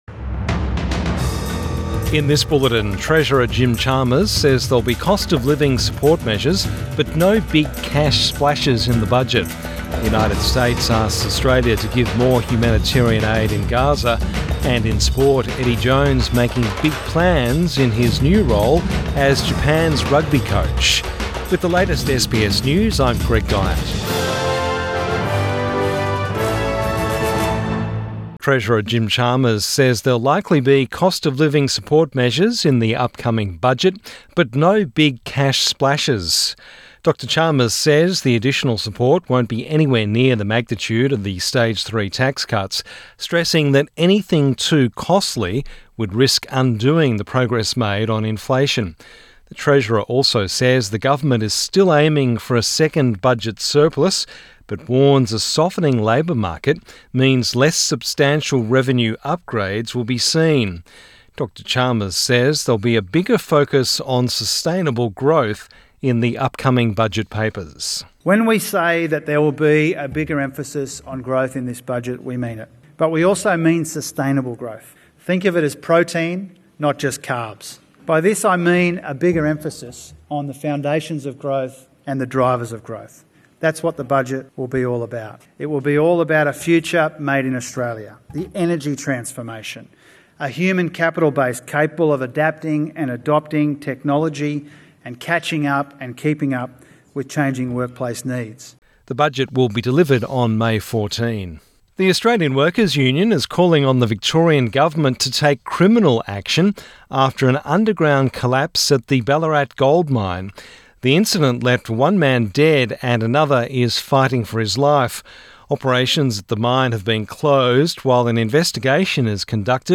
Evening News Bulletin 14 March 2024